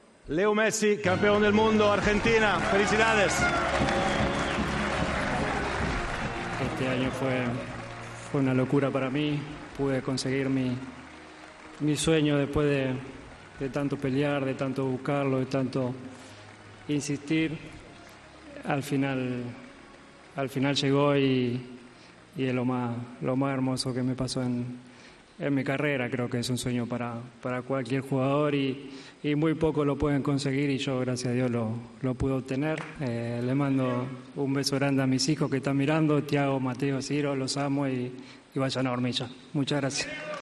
Leo Messi agradece el premio The Best a mejor jugador del mundo